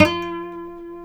F#4 HAMRNYL.wav